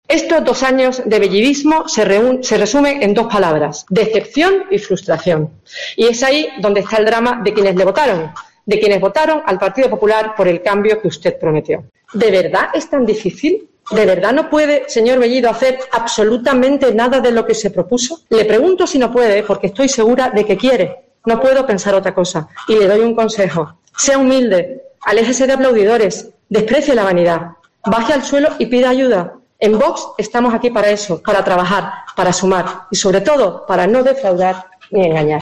Escucha a la portavoz de VOX, Paula Badanelli